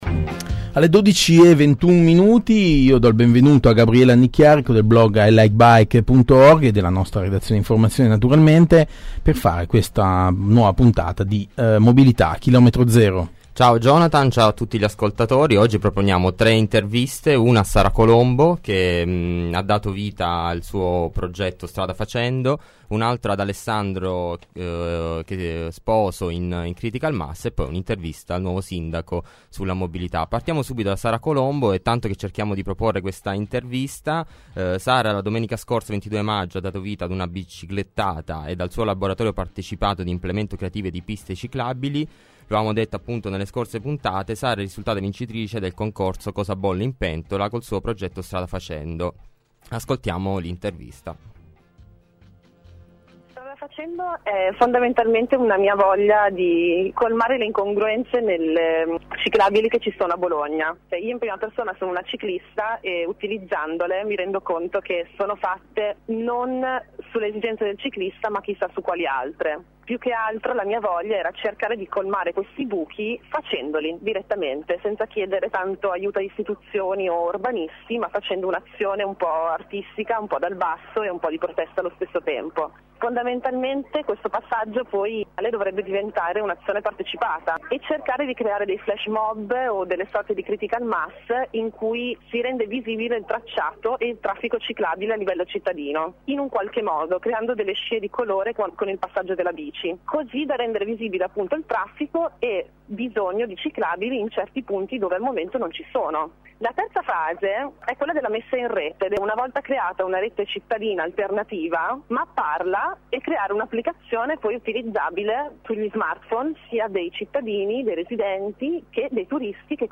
Tre le  interviste.